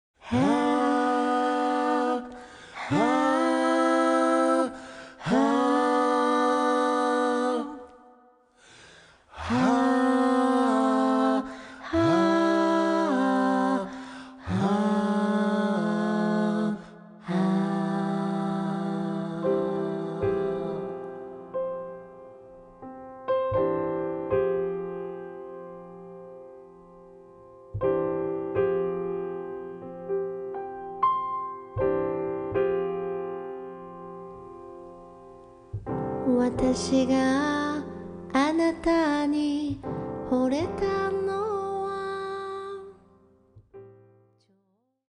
（Folk song）
Recorded on Dec. 22nd and 23rd, 2024 at Studio Dede, Tokyo